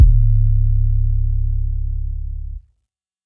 MB 808 (23).wav